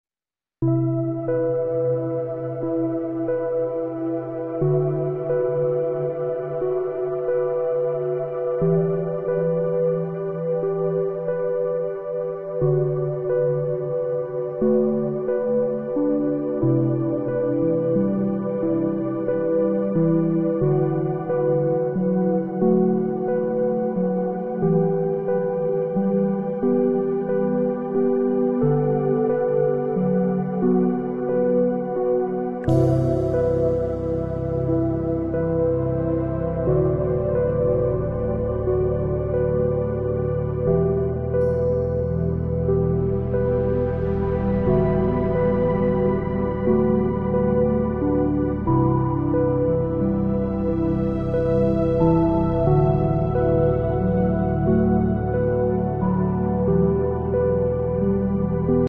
A mountain path under the Milky Way, swirling mist and a mesmerizing sky ,your soothing escape.